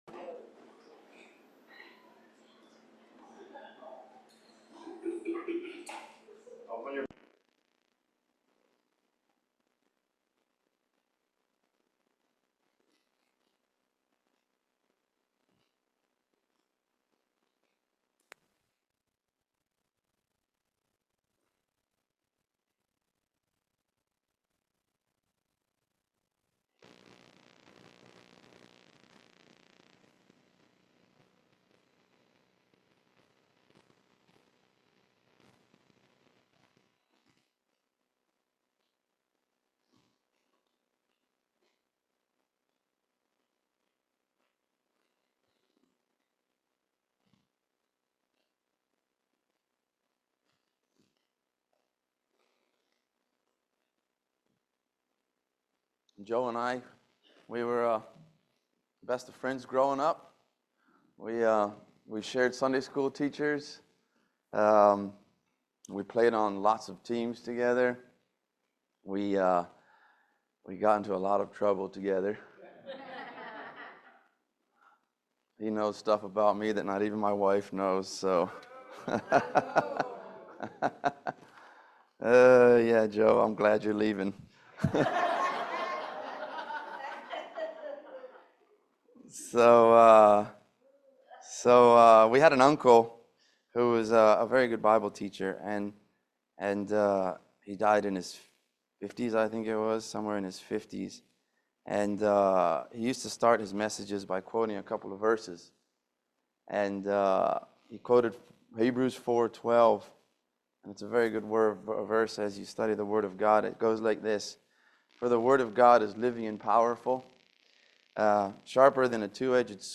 Romans 5:1-11 Service Type: Family Bible Hour There are 7 blessings of justification that apply to the believer’s past